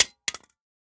magdrop_smg.ogg